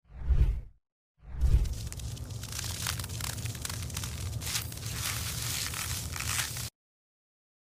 White Crayons Satisfying ASMR Smooth sound effects free download